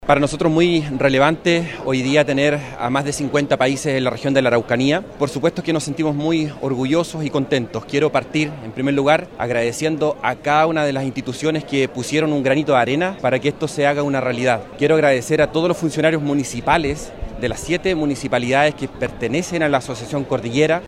Con delegaciones de más de 50 países se inauguró en el Teatro Municipal de Temuco, región de La Araucanía, la undécima conferencia internacional de Geoparques de la Unesco, que se realiza por primera vez en Sudamérica.
El alcalde de Melipeuco y presidente de la Asociación de Municipalidades Cordillera, Alejandro Cuminao, agradeció las gestiones para llevar adelante esta conferencia.